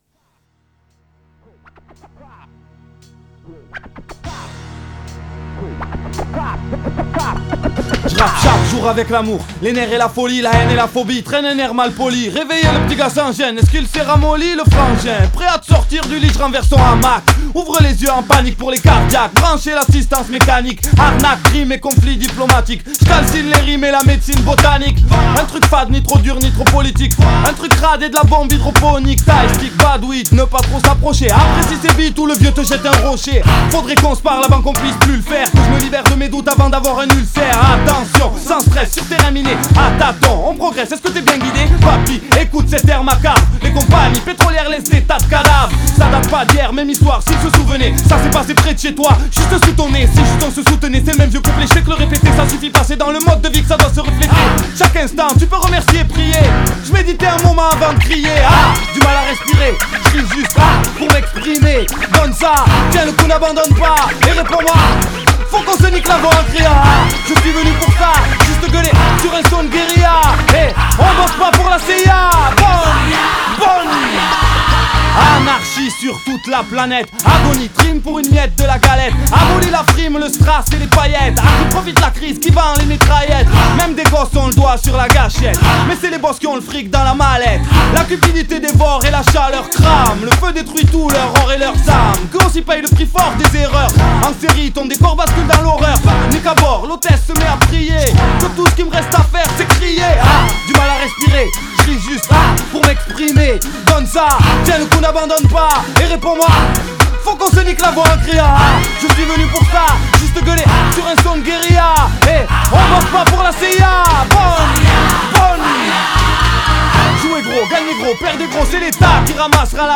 Genres : french rap